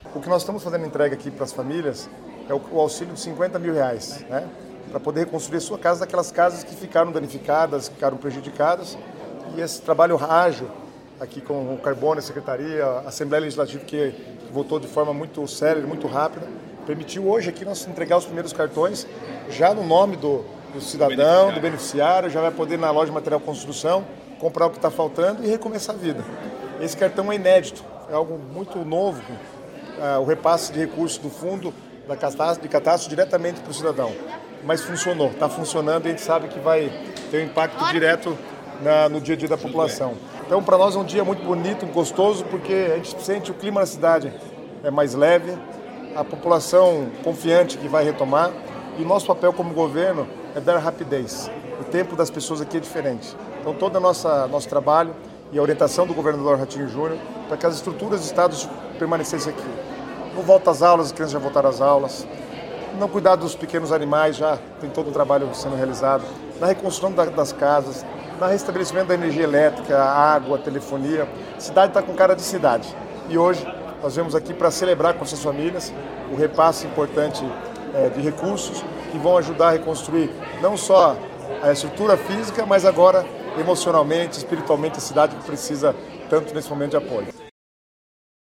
Sonora do secretário das Cidades, Guto Silva, sobre a entrega dos primeiros cartões do programa Reconstrução